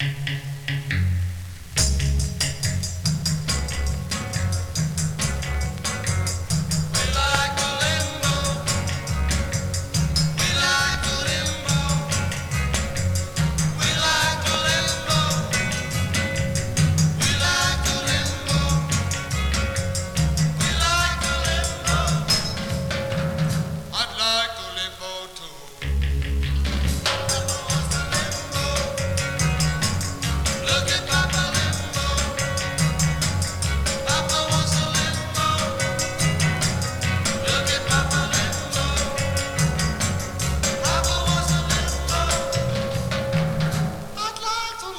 どれもが踊れて、音もやたら良い爽快快活な1枚です。
Rock'N'Roll, Surf, Limbo 　USA　12inchレコード　33rpm　Stereo